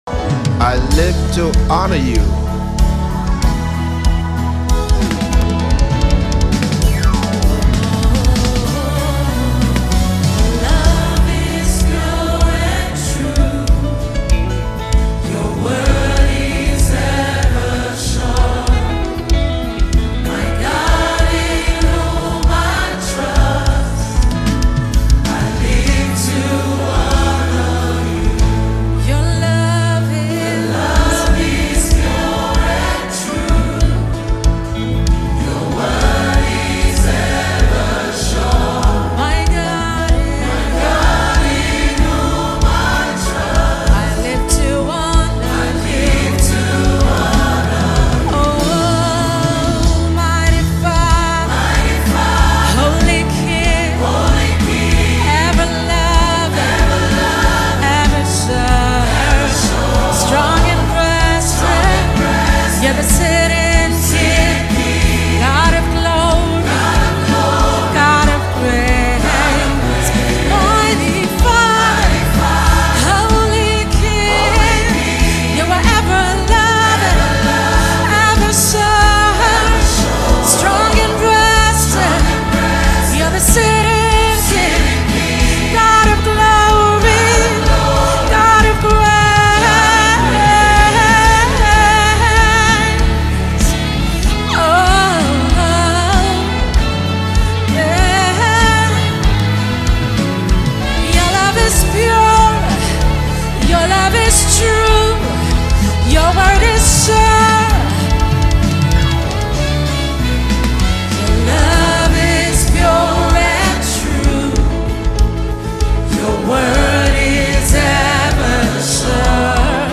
February 11, 2025 Publisher 01 Gospel 0